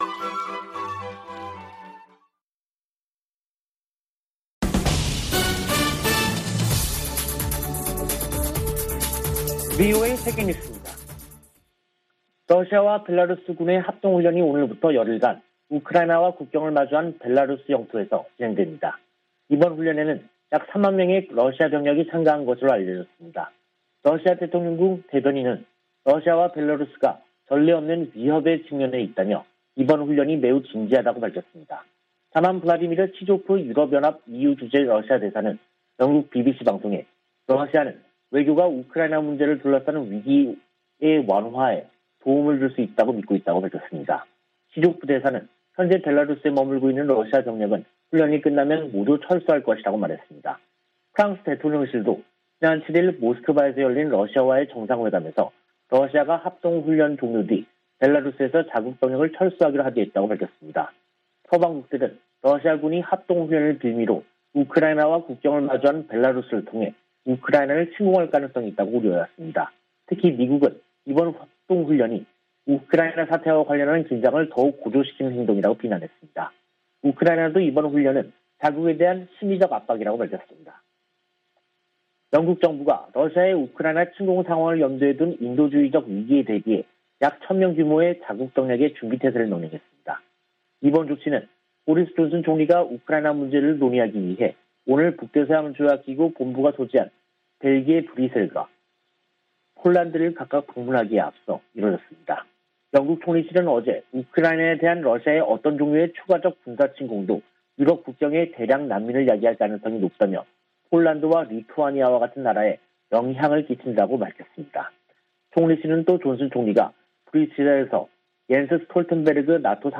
VOA 한국어 간판 뉴스 프로그램 '뉴스 투데이', 2022년 2월 10일 3부 방송입니다. 토니 블링컨 미 국무장관은 이번 주 미한일 외교장관 회동이 북한의 도전 등 의제를 전진시킬 중요한 순간이라고 밝혔습니다. 미국 전직 관리들은 3국 외교장관 회담에서 일치된 대북 메시지가 나오기를 희망하고 있습니다. 문재인 한국 대통령은 남북정상회담에 선결조건이 없지만, 대선 결과가 실현에 영향을 줄 것이라고 말했습니다.